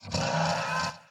骷髅马：嘶叫
骷髅马马在在陆地上空闲时
Minecraft_skeleton_horse_idle3.mp3